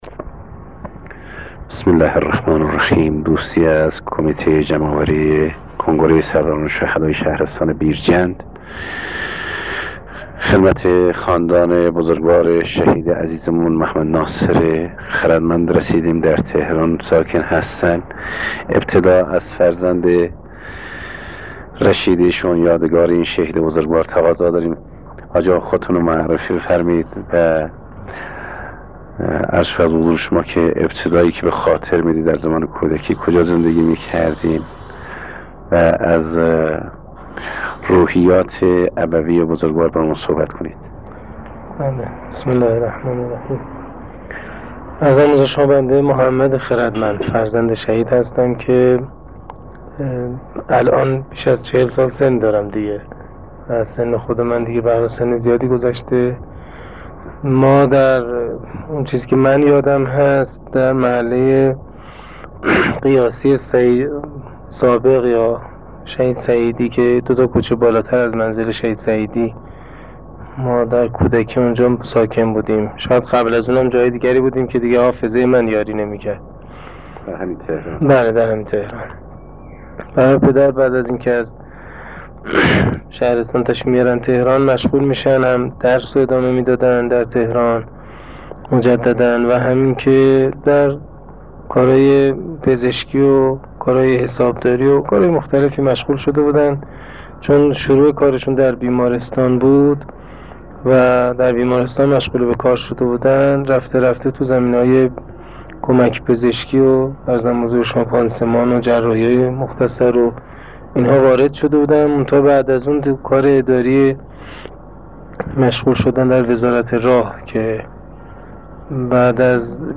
نوید شاهد خراسان جنوبی مصاحبه باخانواده و همرزم شهید